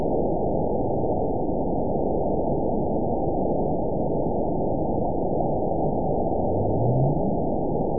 event 911915 date 03/11/22 time 23:13:24 GMT (3 years, 7 months ago) score 9.63 location TSS-AB01 detected by nrw target species NRW annotations +NRW Spectrogram: Frequency (kHz) vs. Time (s) audio not available .wav